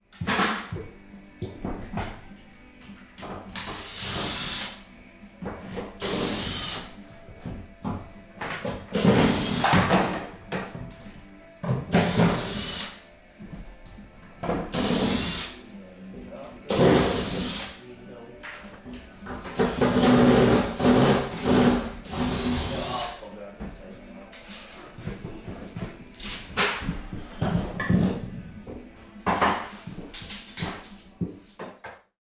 演习
描述：不同间隔的电动螺丝刀
Tag: 机械人 机械 钻头 机械 机器 螺丝刀 机构 机器人 机器人 电钻 电动 OWI 工厂